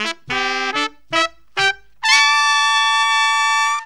HORN RIFF 4.wav